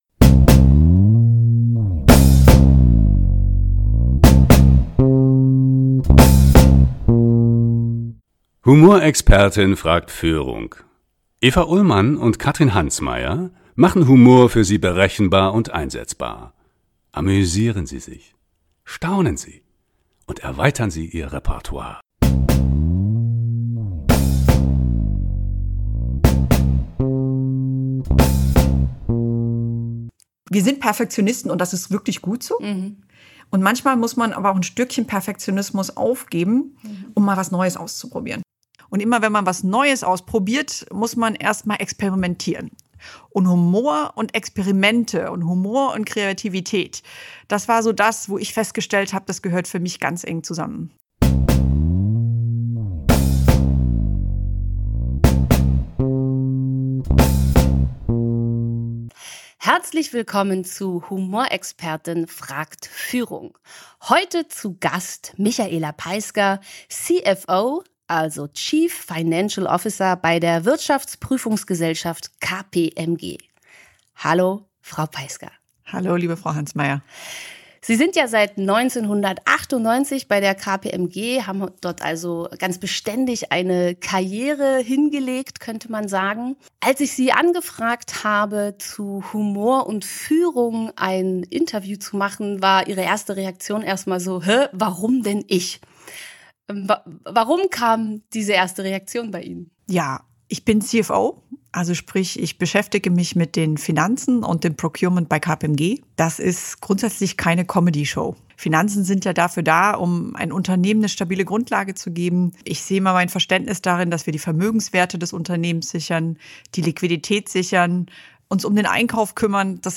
Wo er dringend nötig ist und wo eher seine Abwesenheit hilfreich ist. Zwei Frauen unter sich, natürlich geht es auch um Humor und Schlagfertigkeit in schwierigen Situationen.